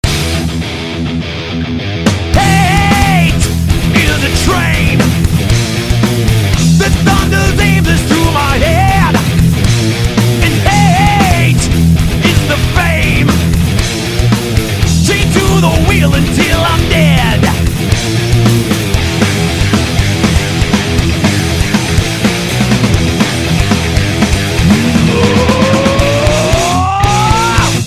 rock n roll